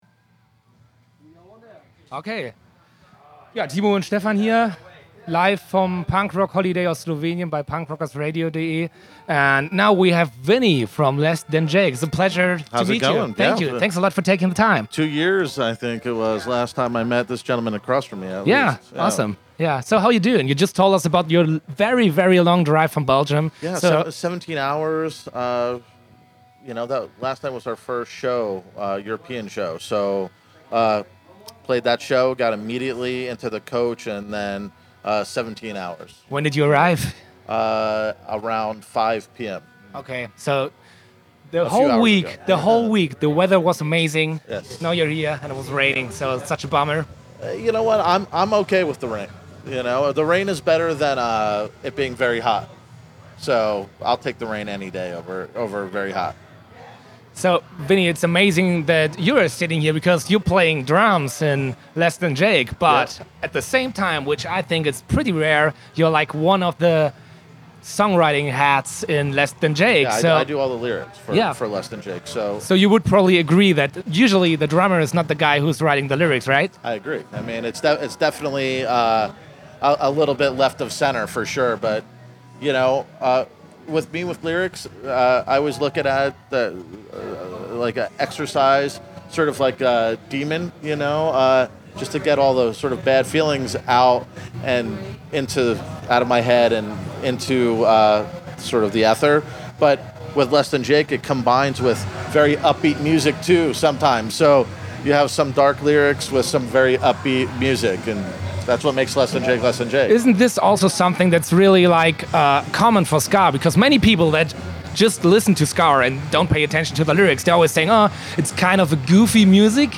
Letzte Episode Interview mit Less Than Jake @ Punk Rock Holiday 1.7 9.
interview-mit-less-than-jake-punk-rock-holiday-1-7-mmp.mp3